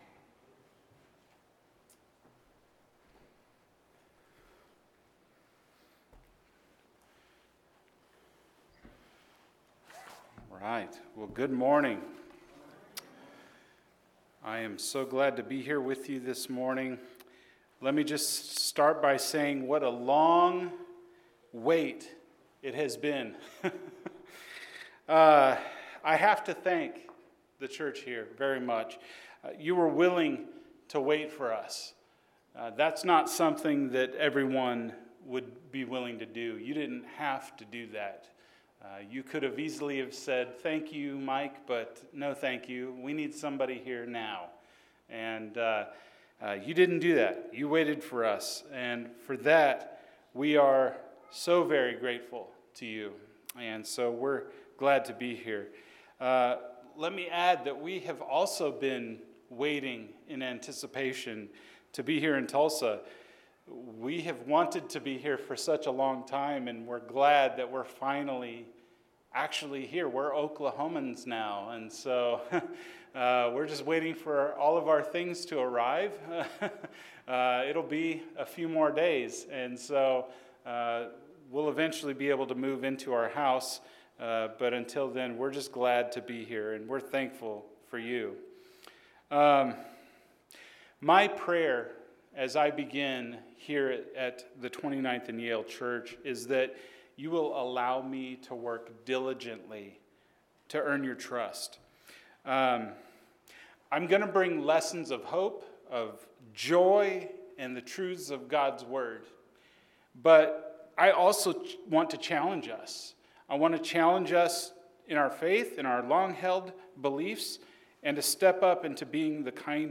You Had to Be There – Sermon